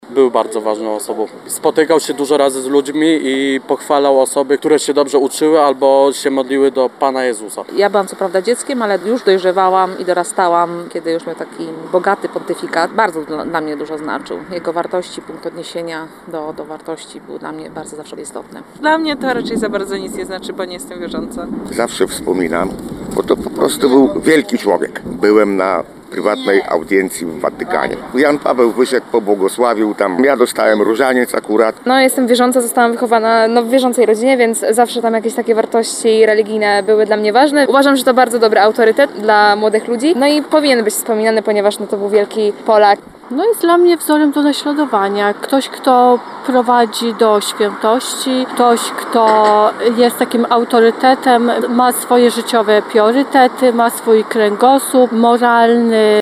Dziś, w okrągłą rocznicę jego śmierci przeszliśmy się ulicami Lublina, żeby zapytać mieszkańców, co myślą o Janie Pawle II.
Jan Paweł II SONDA